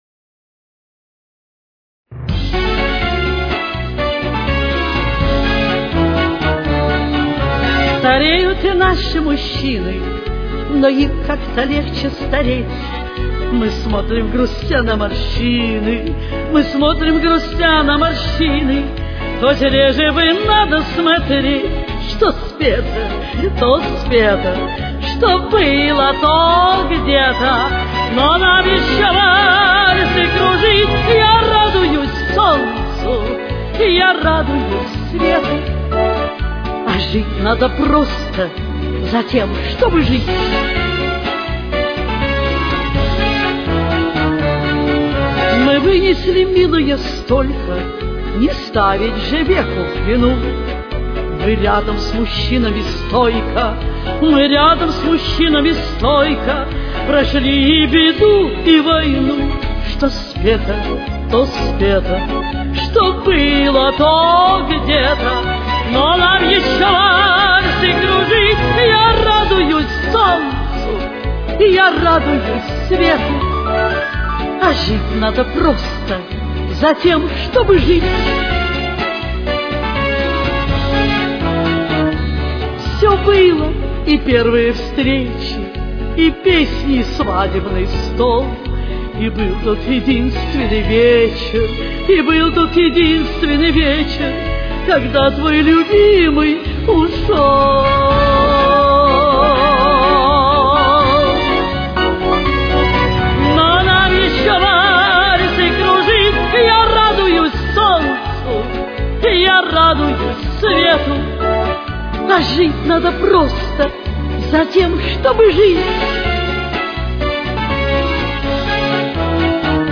с очень низким качеством (16 – 32 кБит/с).
Темп: 252.